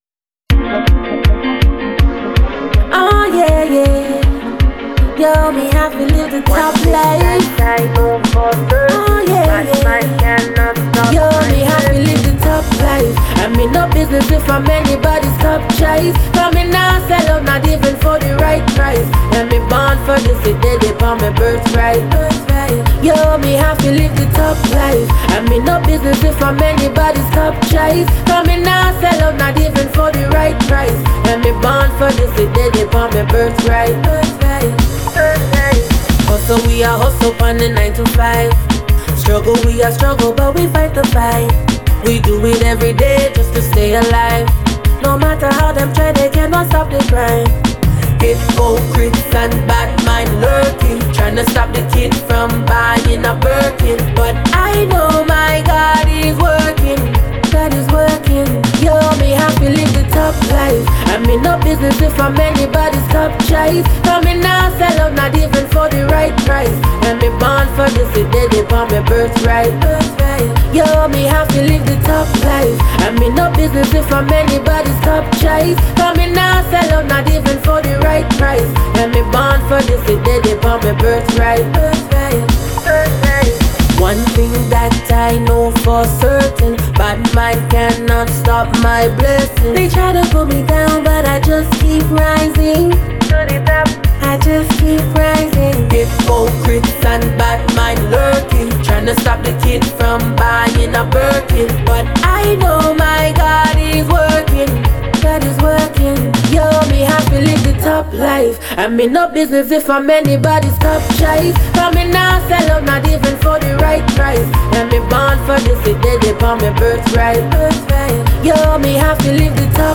• Style: Soca